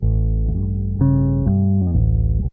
BASS 6.wav